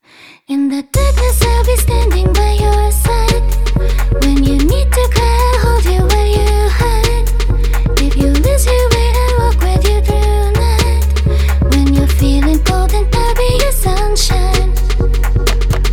一方Vに変えた方はそこでグイッと音楽に勢いが生まれていて、元々の穏やかでメロウな雰囲気とはかなり変貌してしまいました。